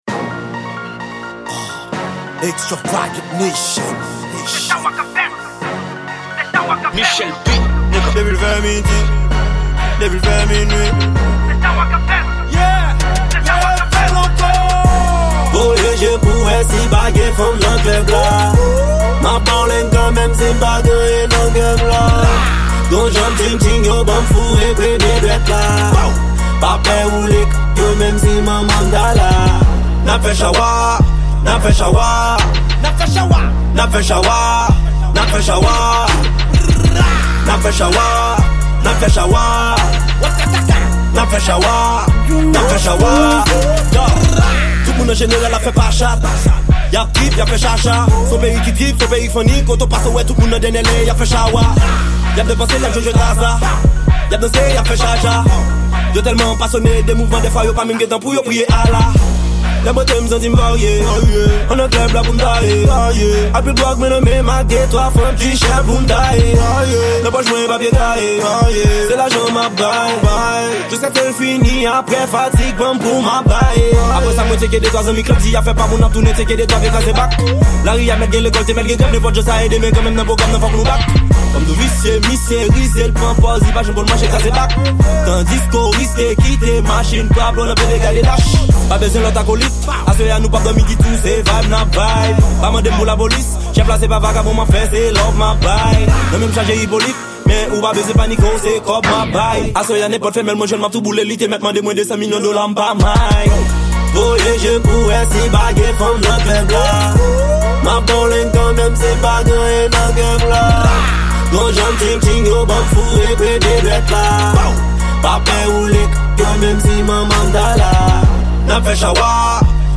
Genre&: Rap